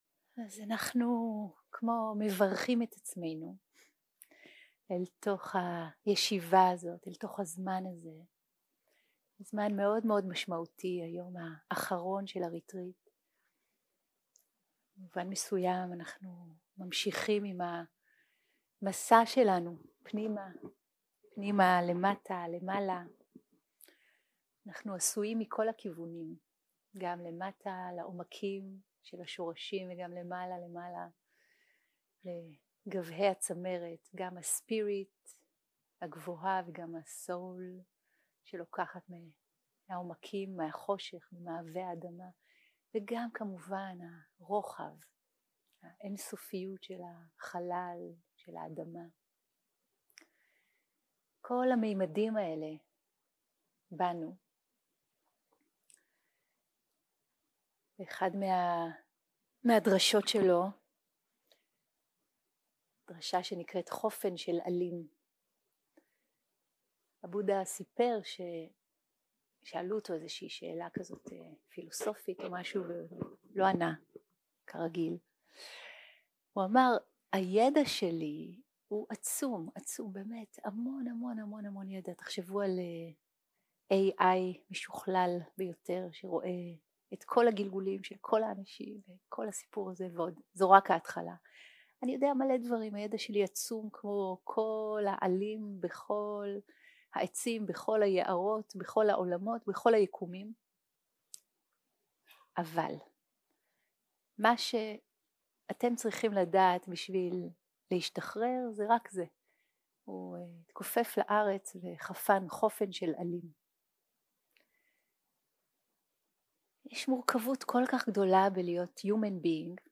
יום 4 - הקלטה 8 - בוקר - הנחיות למדיטציה - להיות עם מה שיש 0:00 0:00 סוג ההקלטה: Dharma type: Guided meditation שפת ההקלטה: Dharma talk language: Hebrew